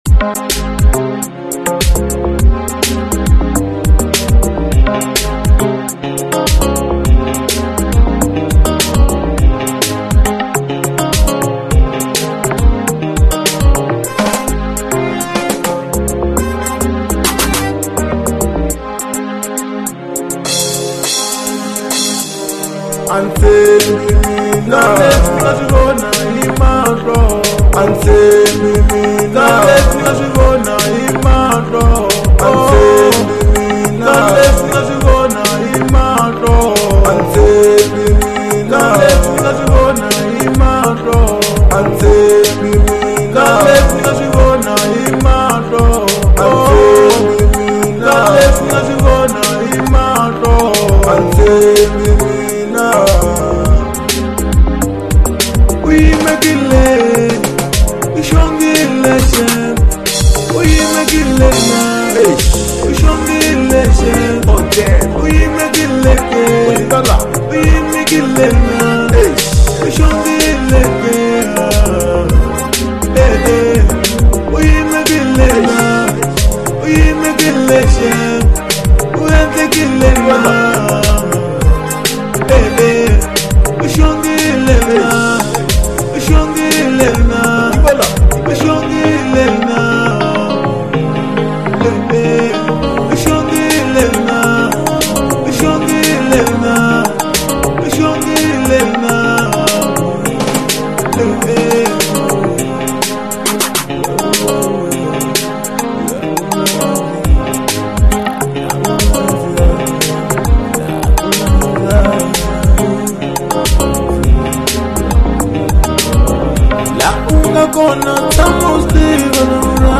03:32 Genre : RnB Size